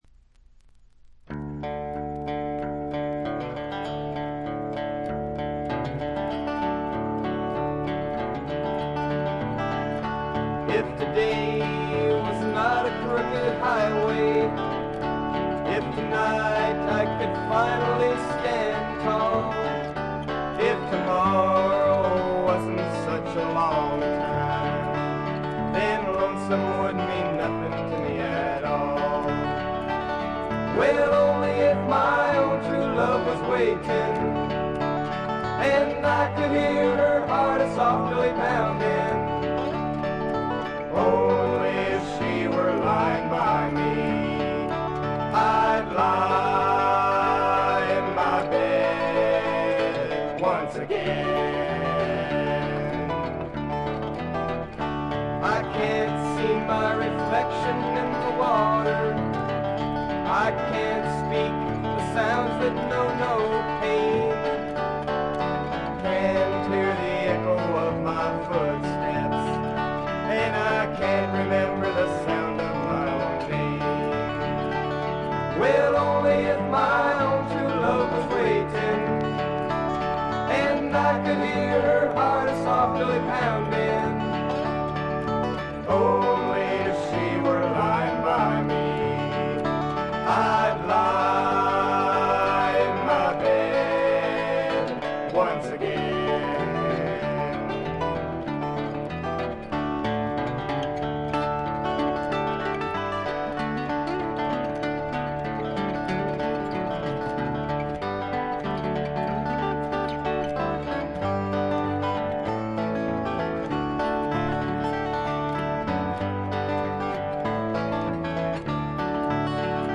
個人的にはジャケットの写真にあるようにフルアコ2台のエレクトリック・ギターの音が妙にツボに来ます。
試聴曲は現品からの取り込み音源です。
Guitar, Banjo, Vocals